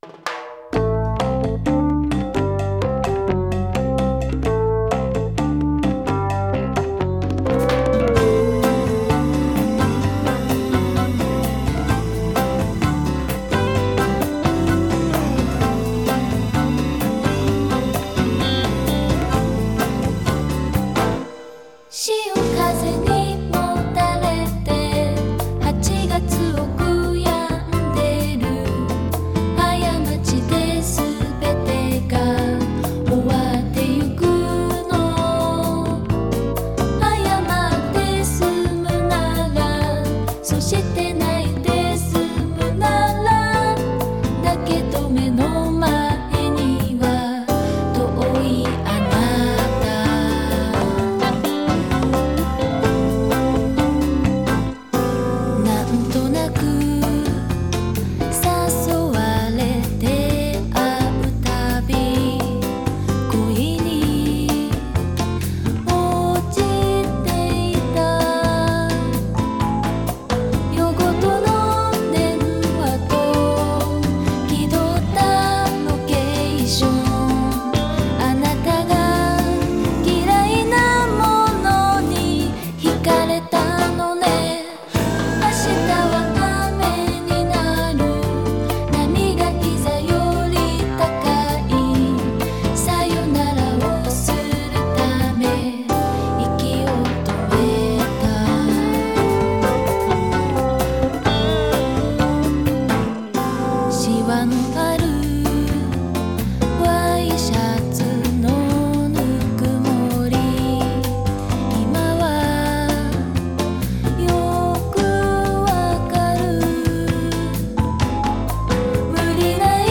Genre: DOMESTIC(J-POPS).